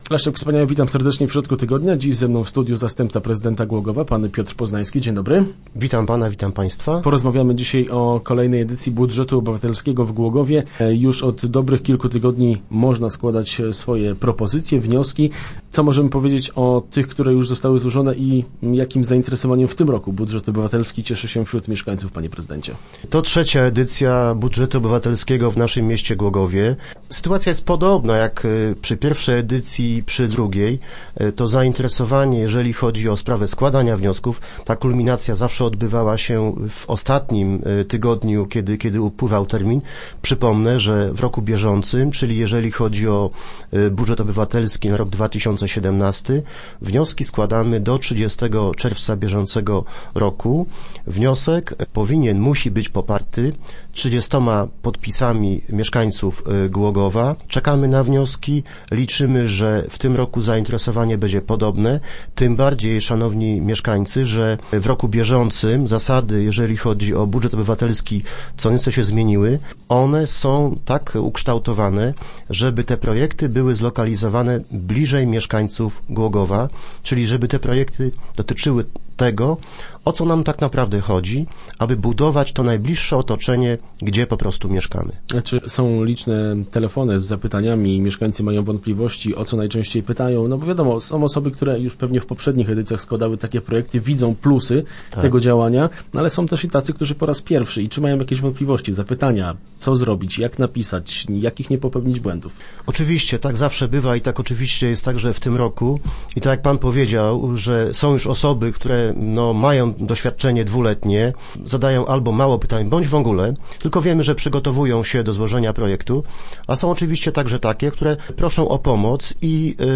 W środę w radiowym studiu gościliśmy Piotra Poznańskiego. Zastępca prezydenta miasta opowiedział o zainteresowaniu kolejną edycją budżetu obywatelskiego oraz przypomniał ważne terminy z nim związane.